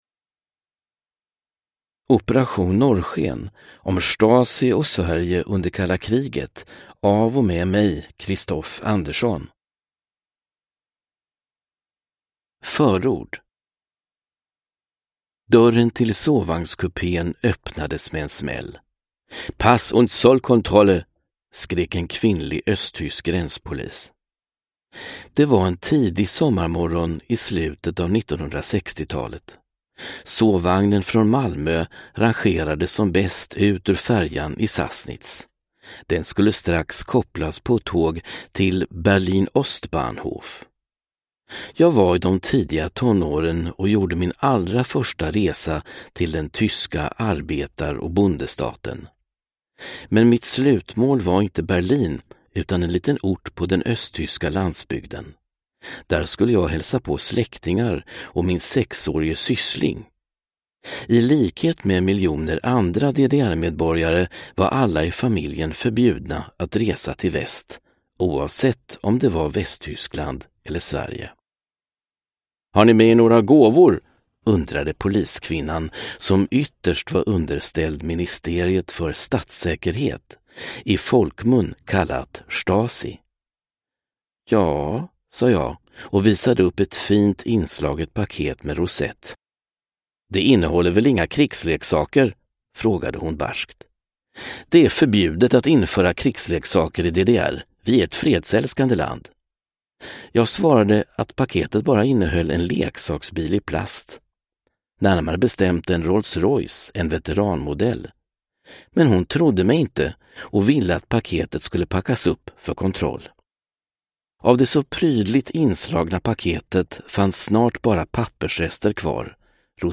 Operation Norrsken : om Stasi och Sverige under kalla kriget – Ljudbok – Laddas ner